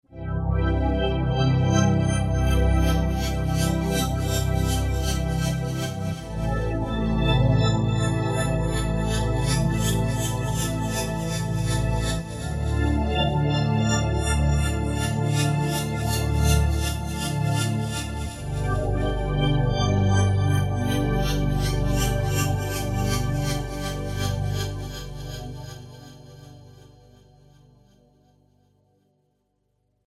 Class: Synth module
vector-like